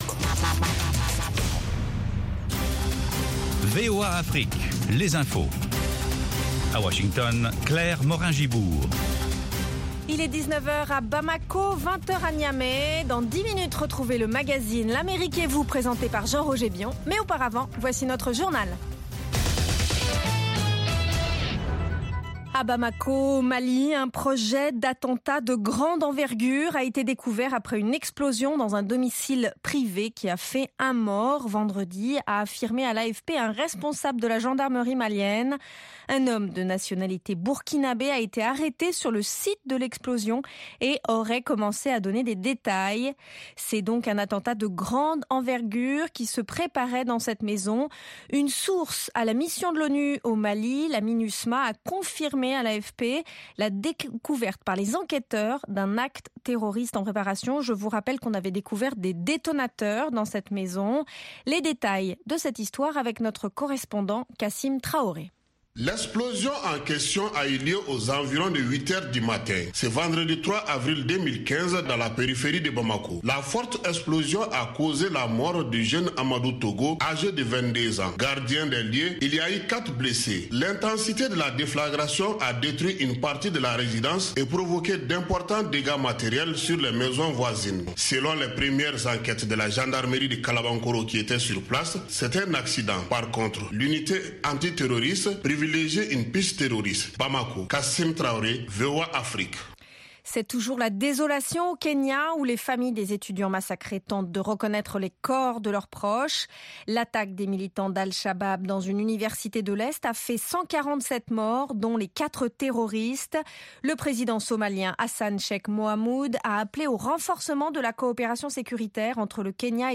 Bulletin
Newscast